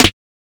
Snare (Lights).wav